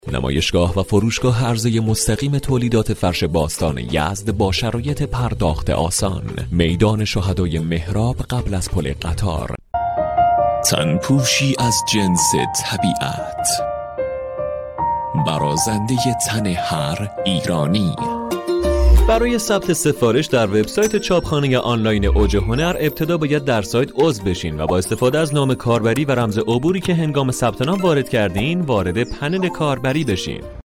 Male
Young
Adult
Now, he has his own home studio and can energetically read and record any type of script you can imagine.
Commercial-02